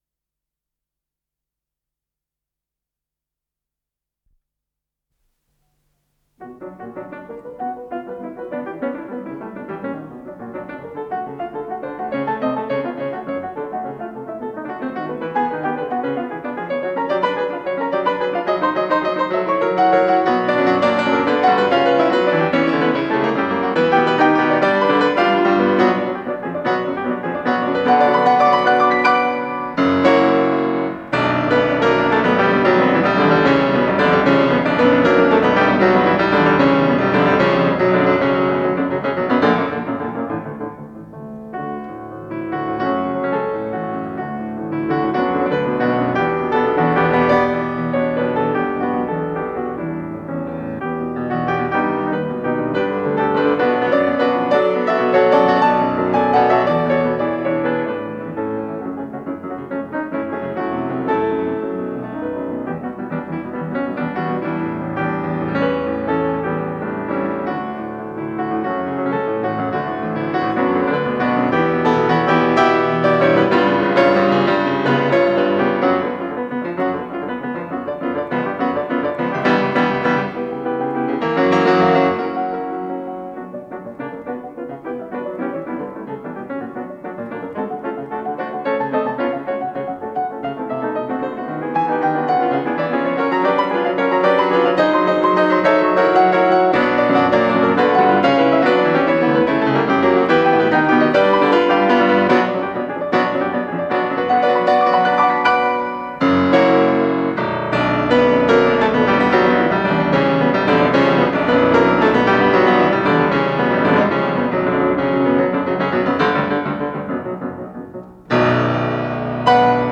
с профессиональной магнитной ленты
ИсполнителиВиктор Мержанов - фортепиано
ВариантДубль стерео